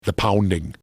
Play, download and share the pounding original sound button!!!!
the-pounding.mp3